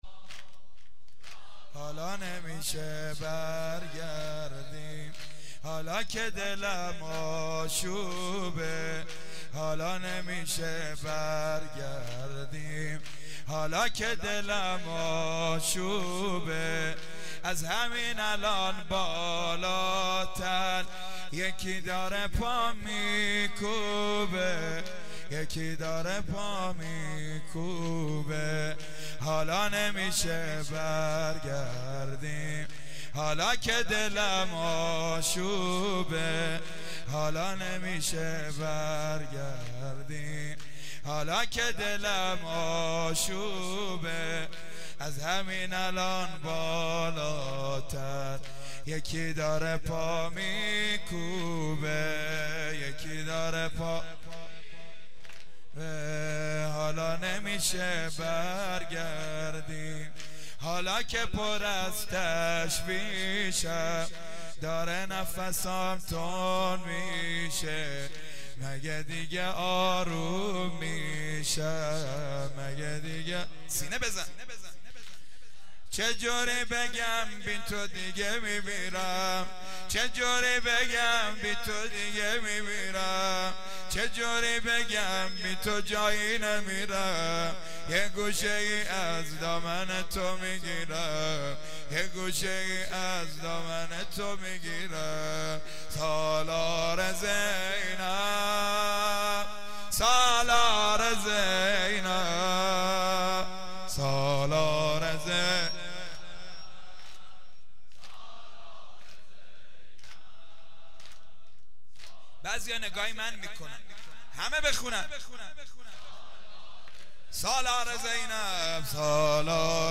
شب دوم محرم درمسجدالرقیه(س) 1393
زمینه.mp3